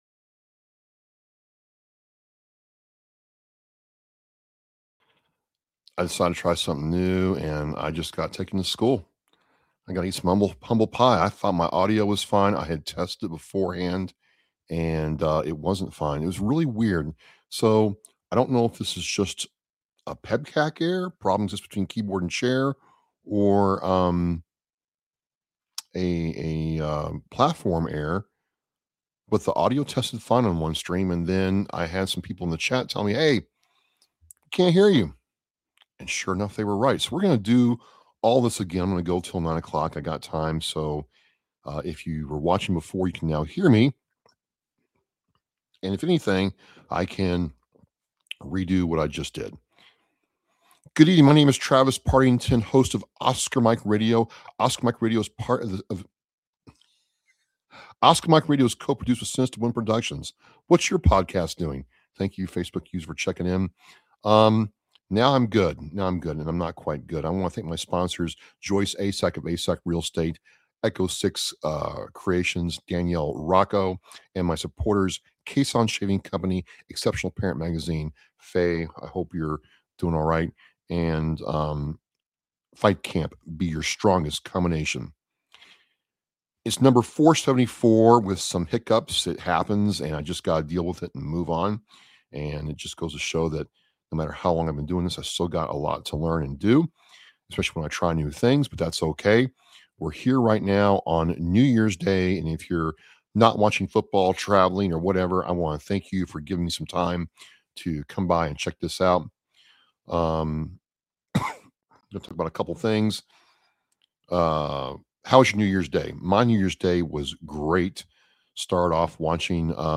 I did a livestream using the OneStream Live Platform. I had a good time bringing in the New Year.